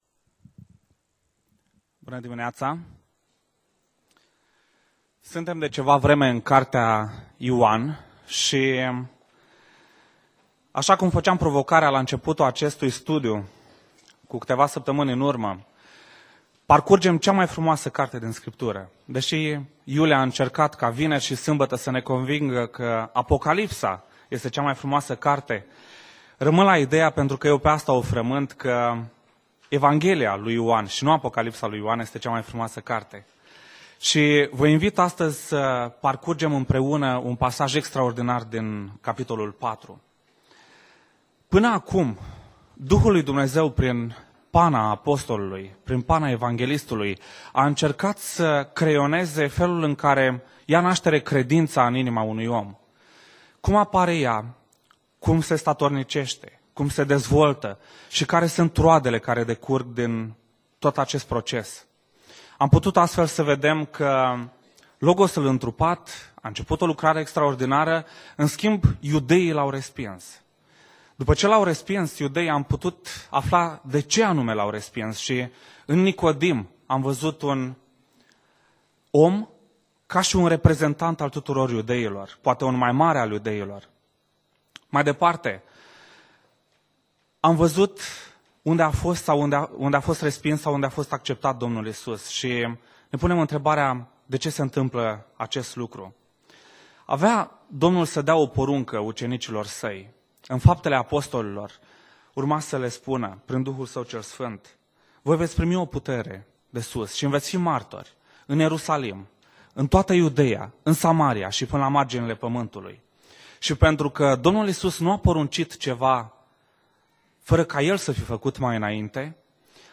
Predica Exegeza - Ev. dupa Ioan cap 4